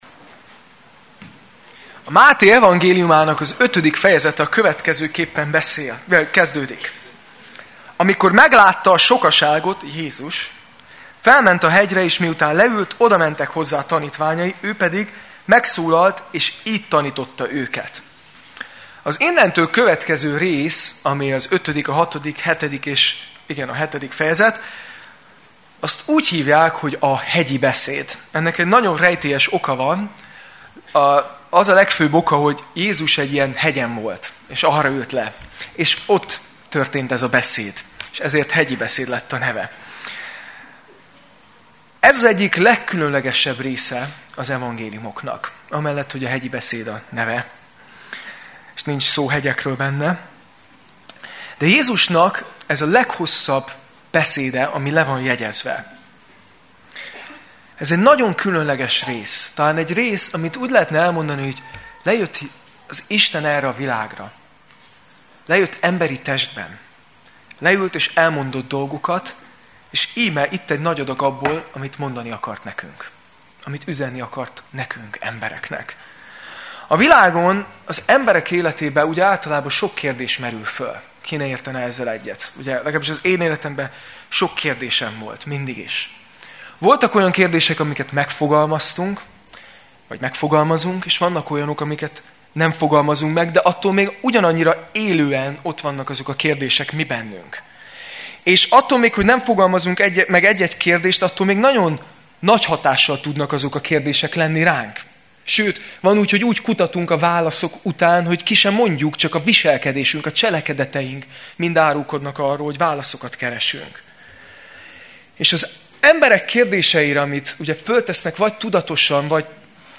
Alkalom: Vasárnap Este